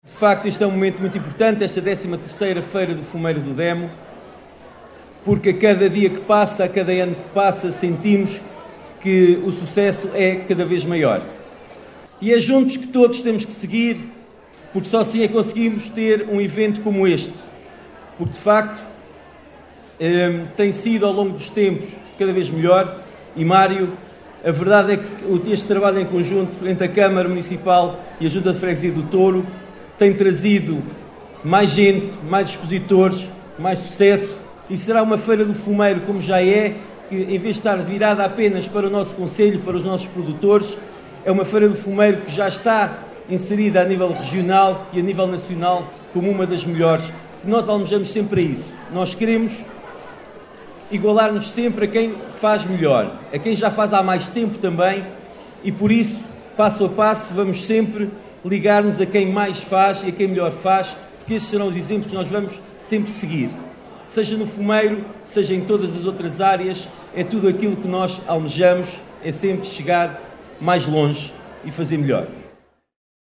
Paulo Marques, Presidente do Município de Vila Nova de Paiva, referiu que “a cada dia que passa, a cada ano que passa, o sucesso desta Feira do Fumeiro é cada vez maior, uma das melhores da região e do país…“.